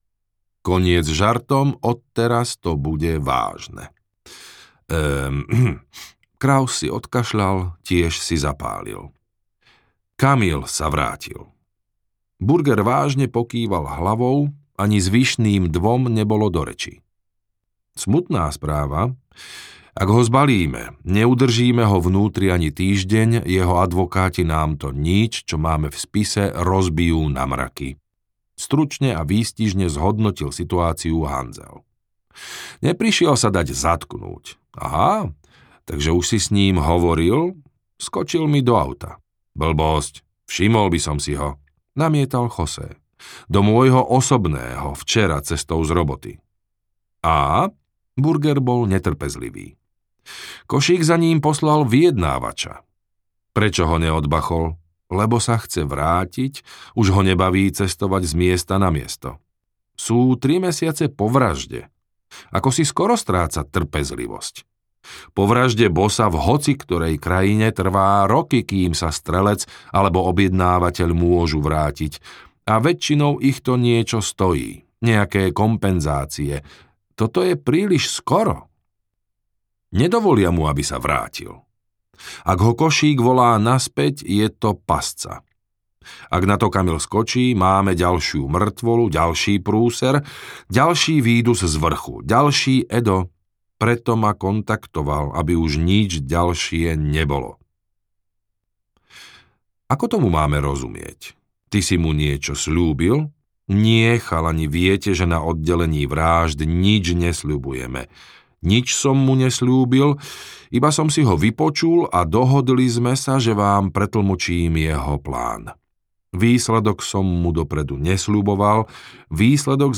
Kruhy na vode audiokniha
Ukázka z knihy
kruhy-na-vode-audiokniha